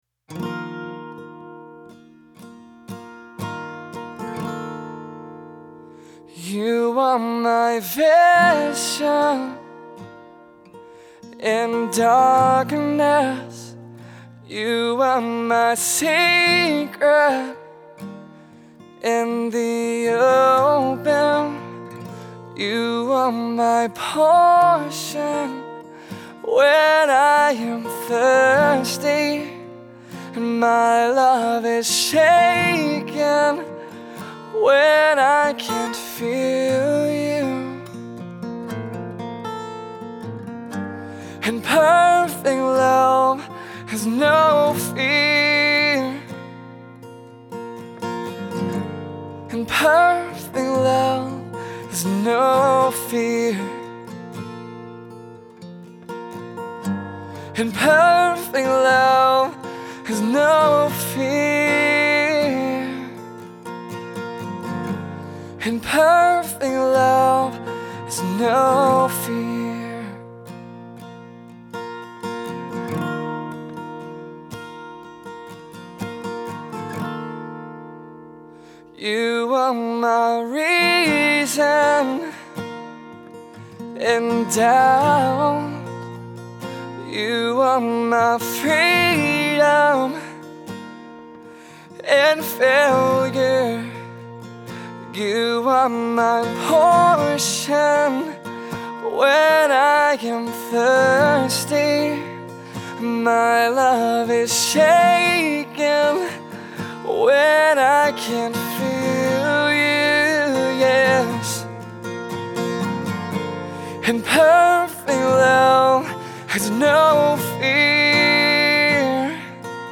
perfect-love-acoustic.mp3